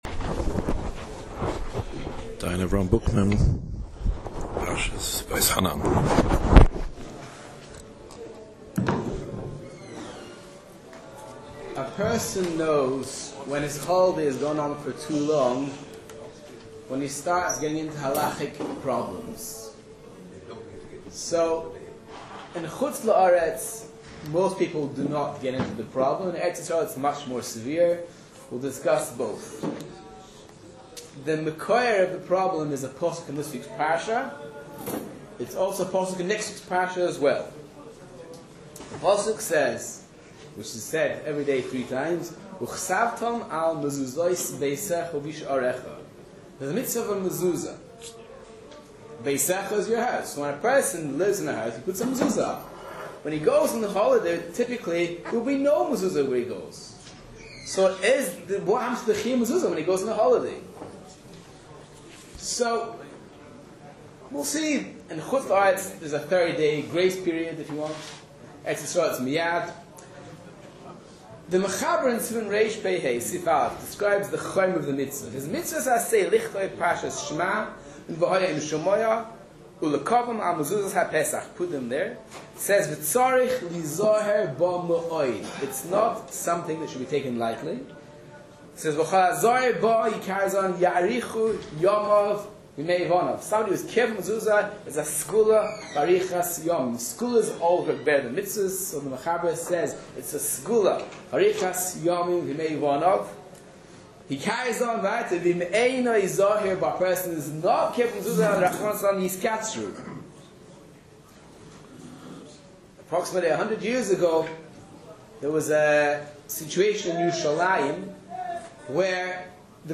Listen to Dayan Avrohom Bookman Scroll down to play the shiur Date 17 August 2016 Speaker Dayan Avrohom Bookman (click to see more details and other shiurim) Title Hilchos Mezuza Summary Dayan Bookman Shlit"a treated us to a delightful, well delivered Shiur regarding Hilchos Mezuza. In many scenarios, a period of 30 days is halachically prescribed as being the minimum required time limit for which there is a chiyuv Mezuzah.